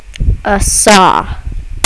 saw2.wav